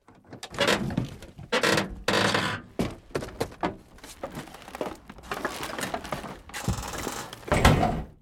wreck_0.ogg